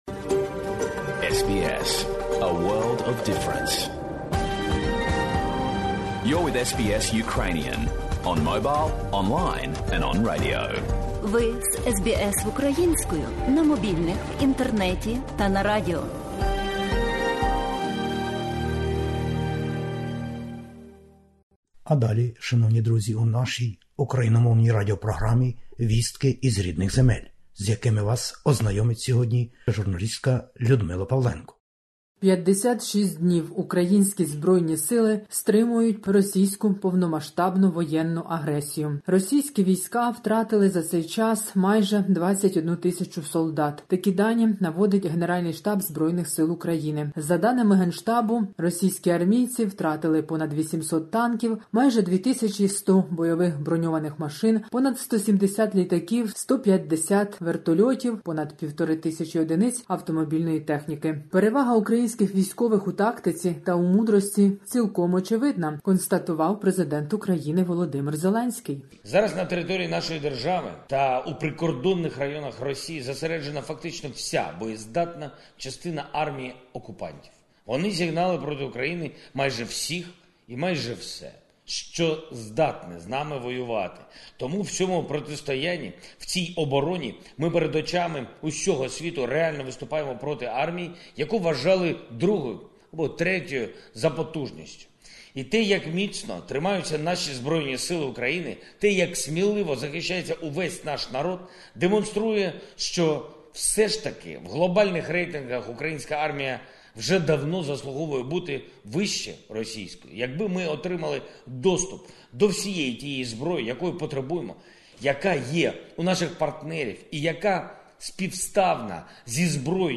Добірка новин із воюючої України спеціально для радіослухачів SBS Ukrainian. Загострення війських дій на Донбасі. Маріуполь в оточенні російськими військами, але мужньо захищається.